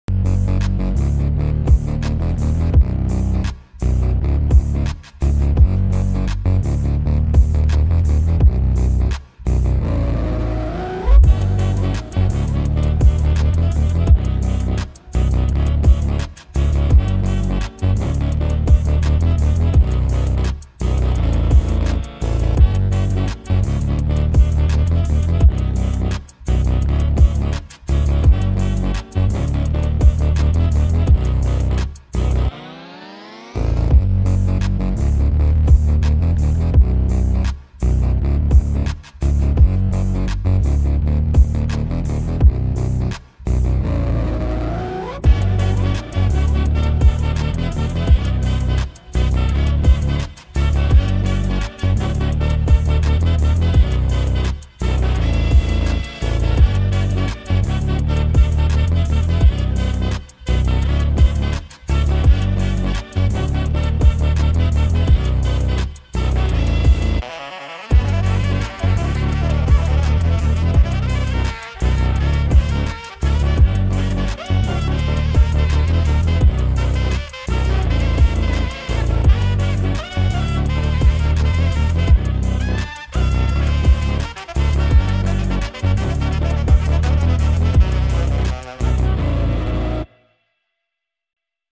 • Качество: 320 kbps, Stereo
[Bass Boosted]